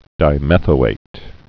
(dī-mĕthō-āt)